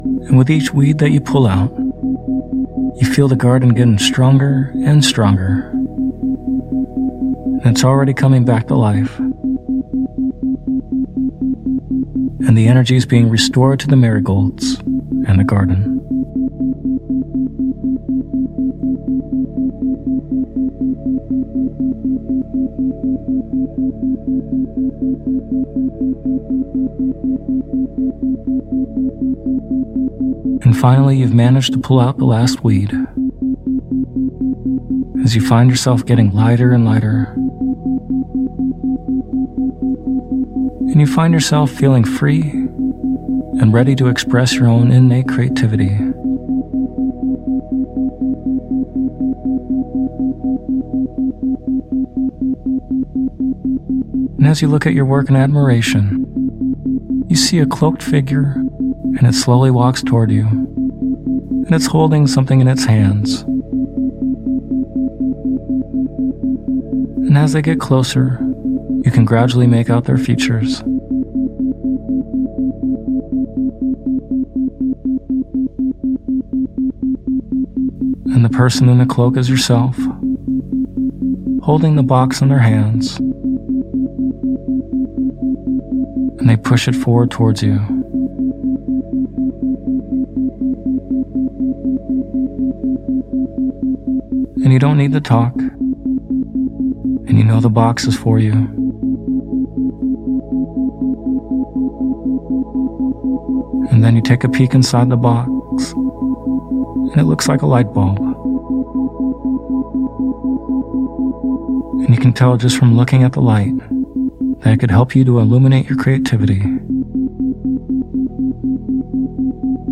Unlocking Your Creativity With Isochronic Tones
In this sleep hypnosis audio, we’ll be releasing the blocks and tapping into the power of your subconscious mind to help unlock your creativity and to allow for innovative ideas.